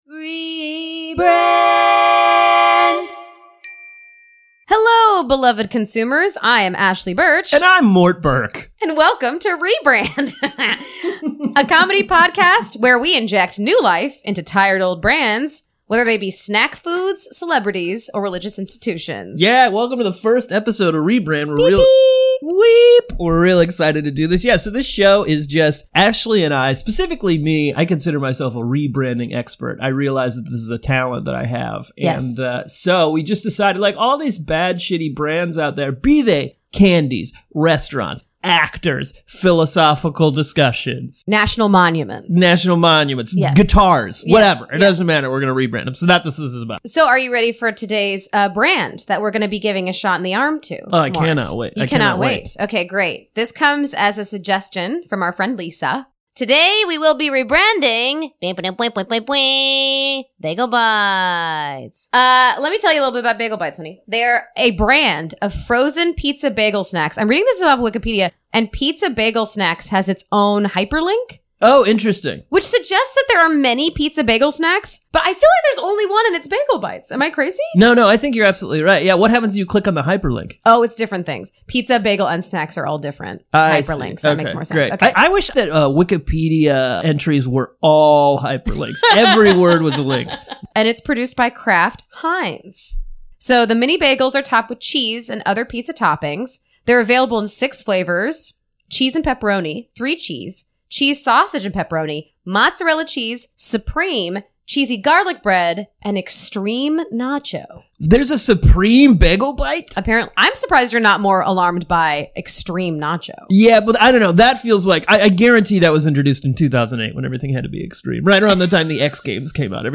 trigger warning there is some brief rapping